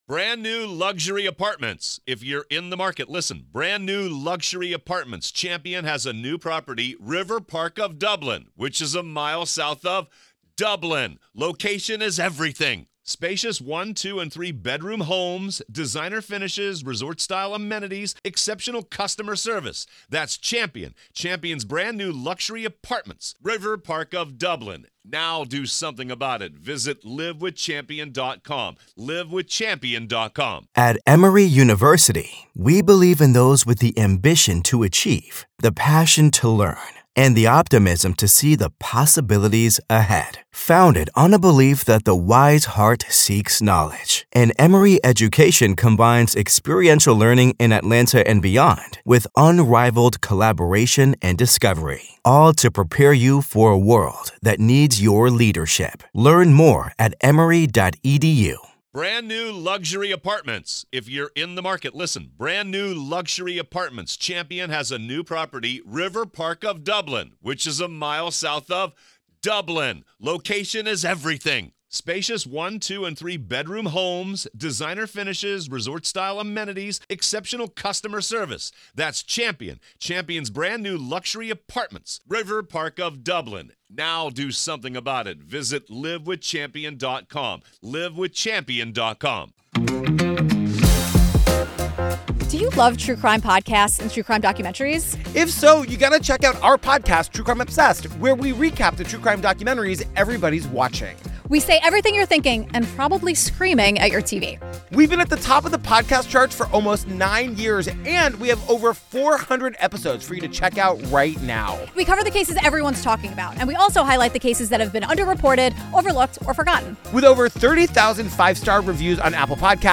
Don't miss a beat of the gripping testimony and explosive evidence as the accused faces life-altering charges for the brutal murder of his own family.